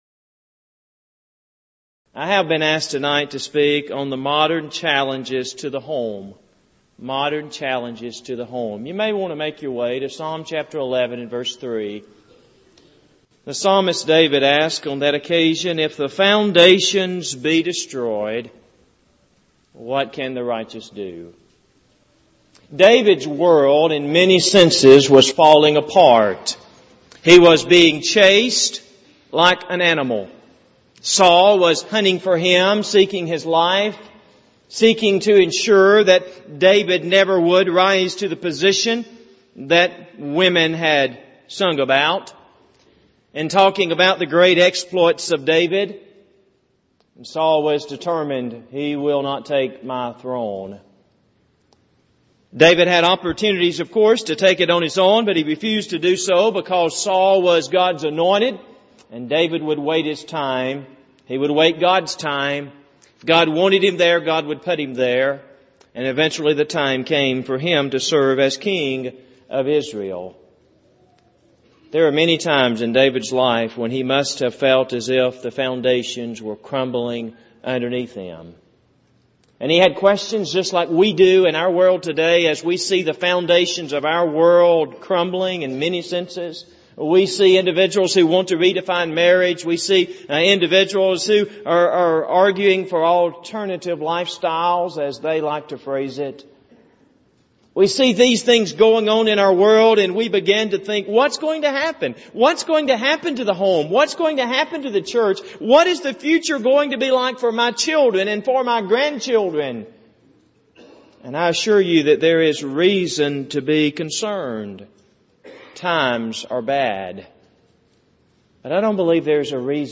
28th Annual Southwest Lectures
audio or video copies of this lecture